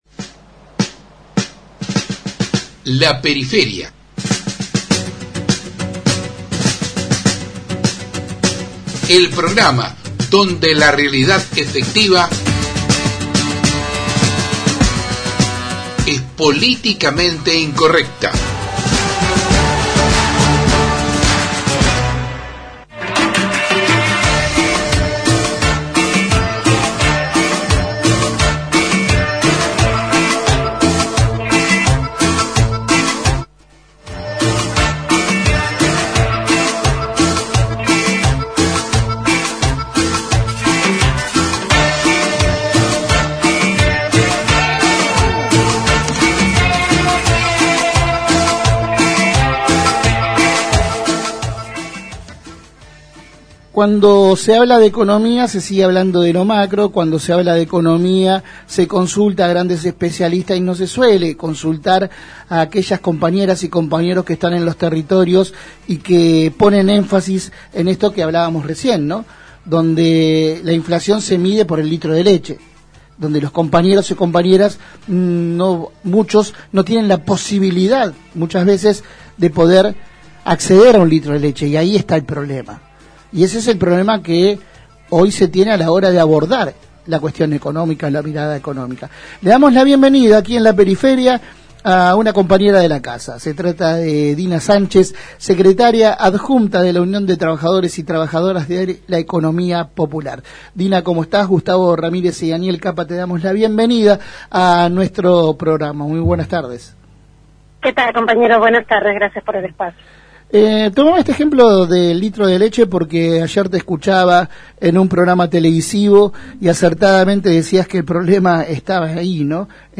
Compartimos la entrevista completa: 20/10/2022